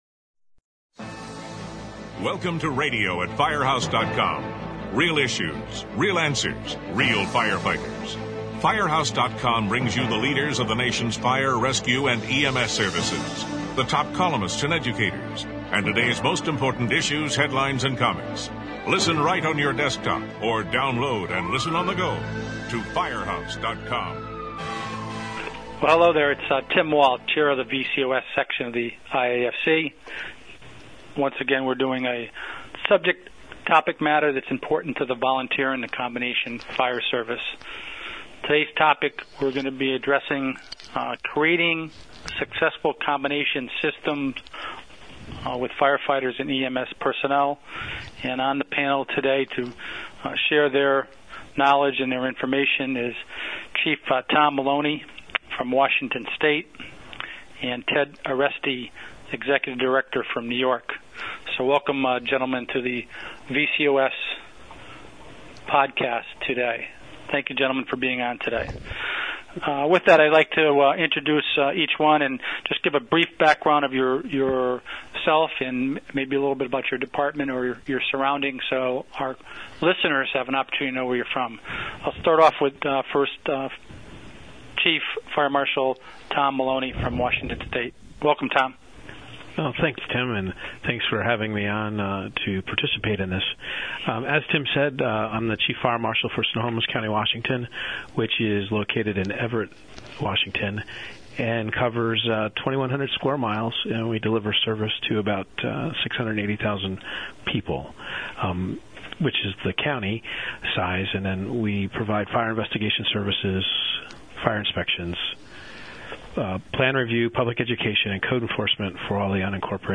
All three are veteran members of combination departments and offer insight into the needs of a department when it's going to tranisition to a combination agency.